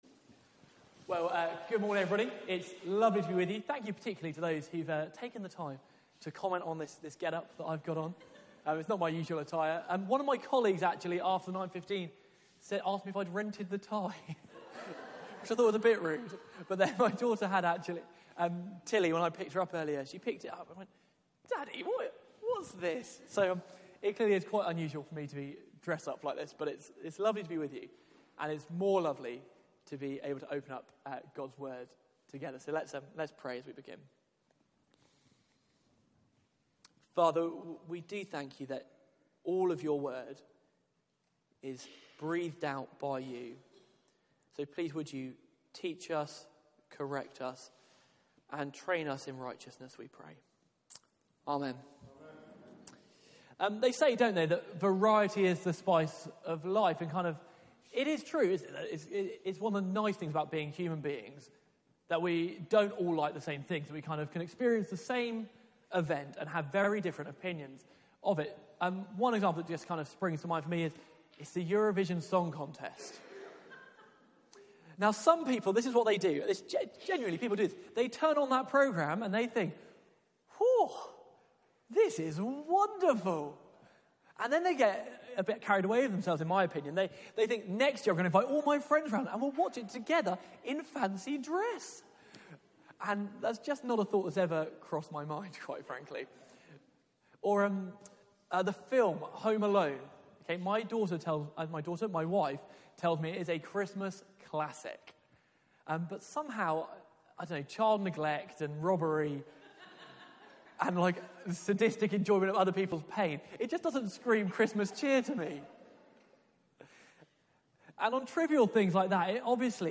Media for 9:15am Service on Sun 14th Oct 2018
Series: The School of Christ Theme: Why people reject Jesus Sermon (11:00 Service)